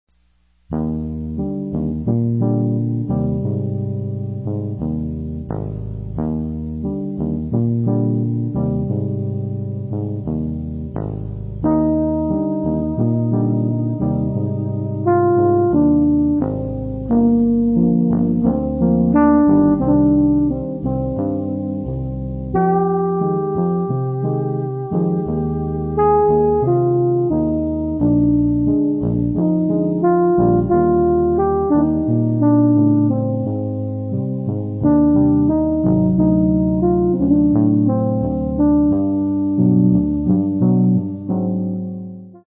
Here's a midi sample of the good part (